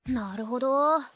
sukasuka-anime-vocal-dataset